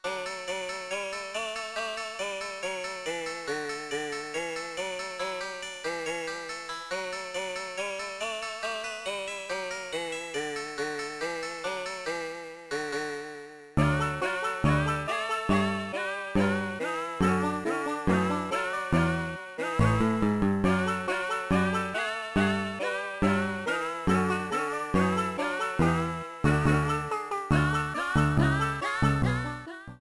Self-recorded from emulator
Fair use music sample